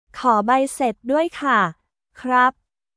コー バイセート ドゥアイ カ／クラップ